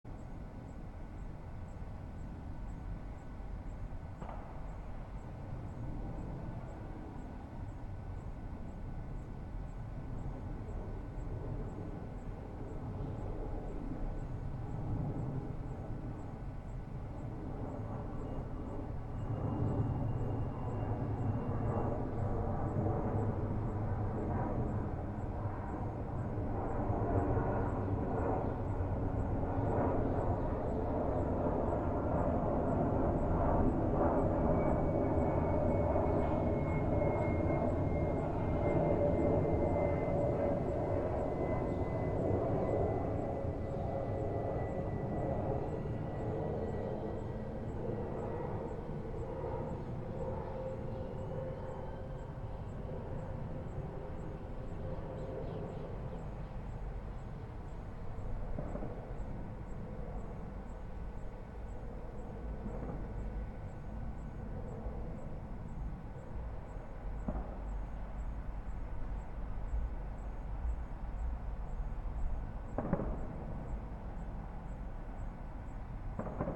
Live from Soundcamp: La Escocesa mixing in Barcelona (Audio) Sep 13, 2025 shows Live from Soundcamp A listening/mixing station will combine live streams of back yards in Barcelona and beyond Play In New Tab (audio/mpeg) Download (audio/mpeg)